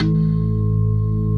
B3PLUCKG#1.wav